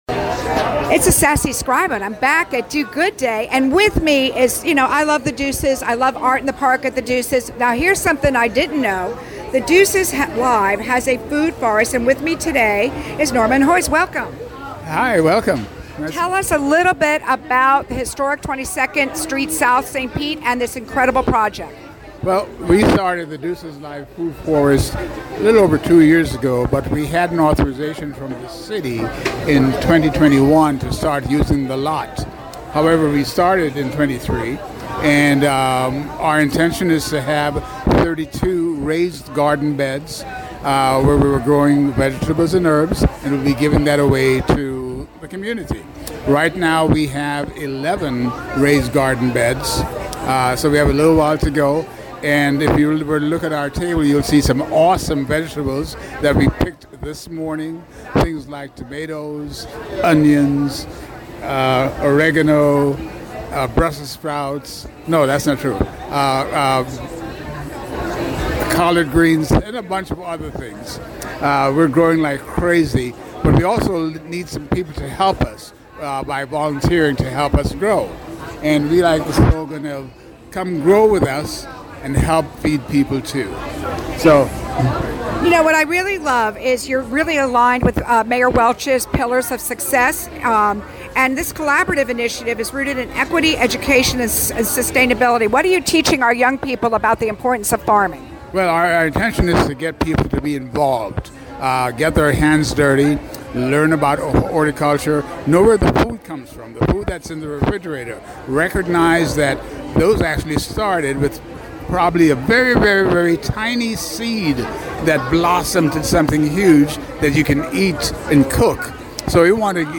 RadioStPete participated in Do Good Day at Fergs Sports Bar in downtown St. Pete March 29, interviewing some of the 35 non-profits sharing their mission and how you can volunteer and donate.